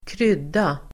Uttal: [²kr'yd:a]